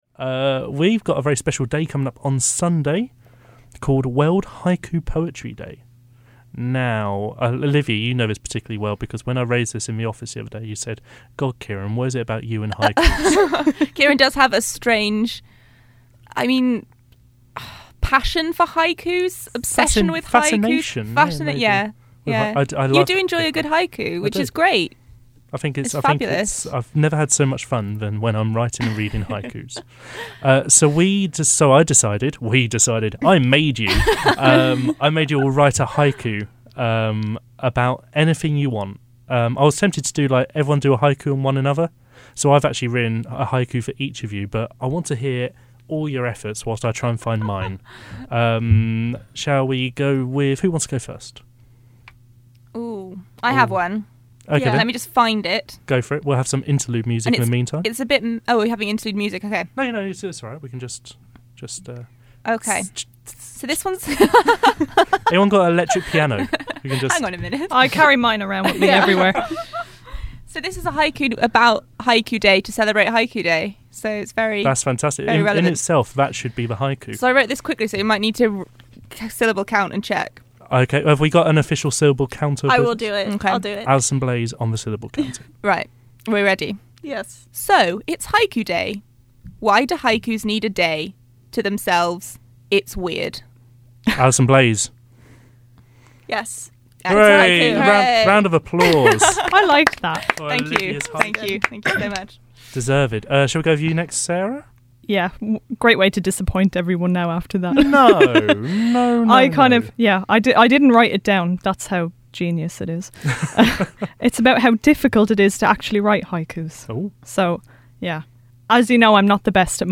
Last week on The Friday Meltdown, the team got together to write haiku’s based on their time in Bournemouth and each other, in celebration of World Haiku Poetry Day. This is the result, as broadcast on the show, of our amateur poet’s efforts.